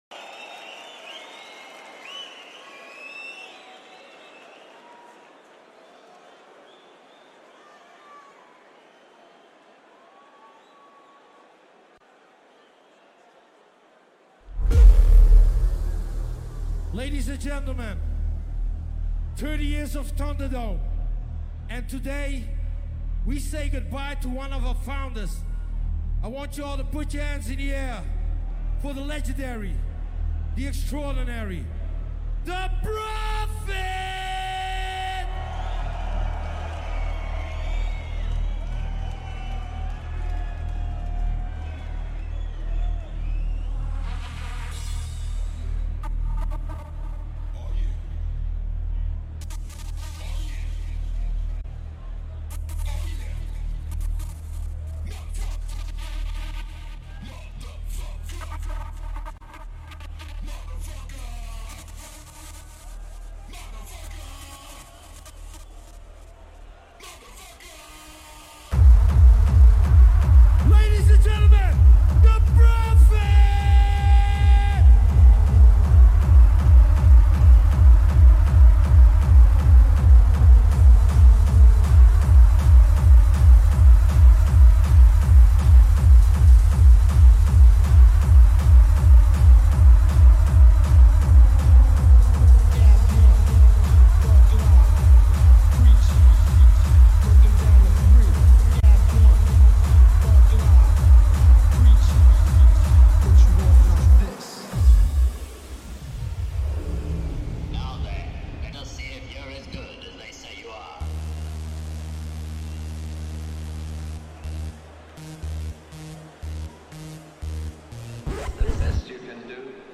Also find other EDM Livesets, DJ